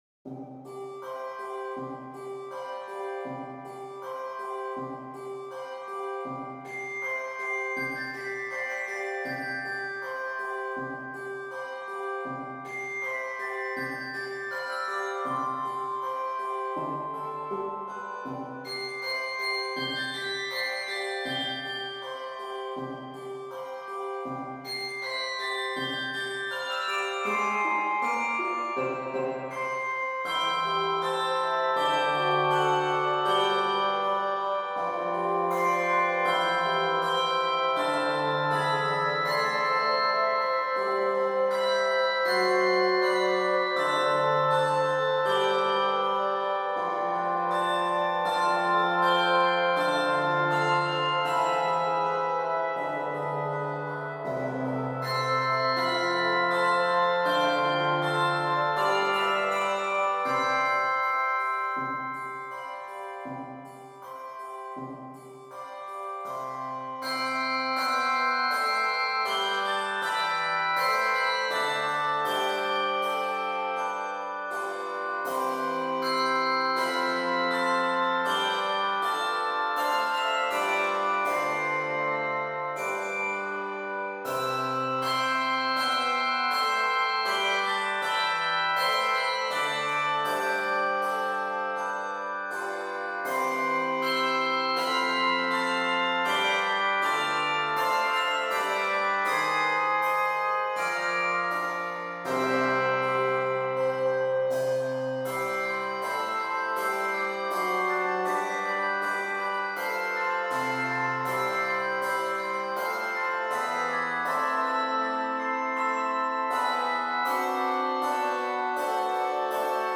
Voicing: Handbells